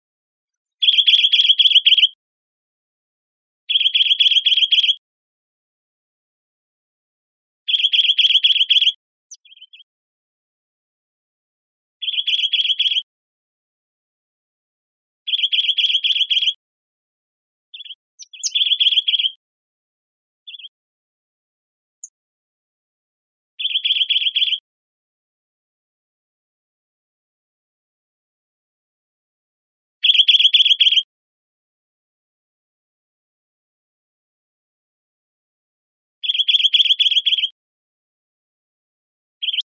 bridledtitmouse.wav